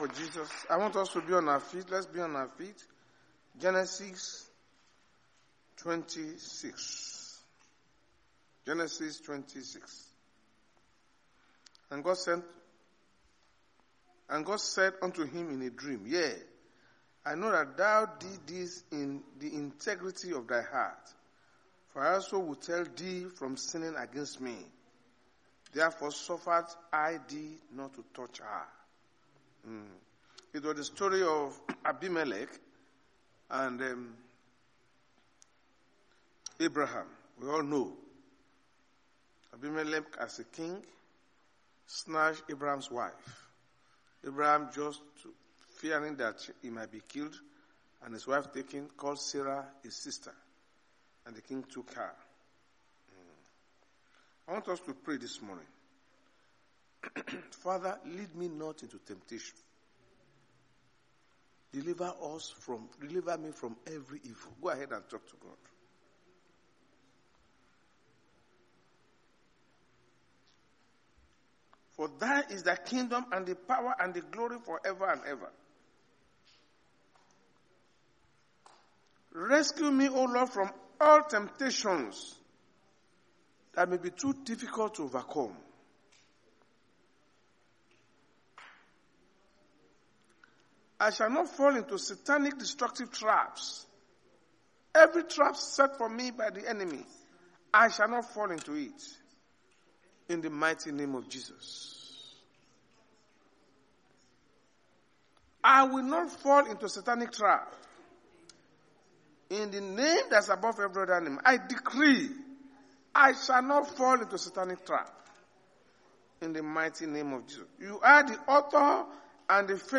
Sunday Sermon: When Good And Evil Are Wrongly Decoded
Service Type: Sunday Church Service